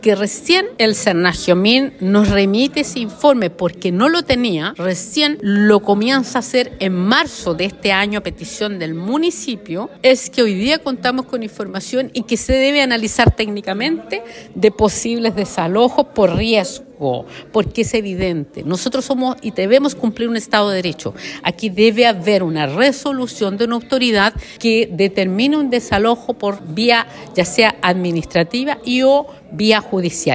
Gajardo además señaló que tanto los hechos como los informes reiteran la necesidad de la reubicación de las familias.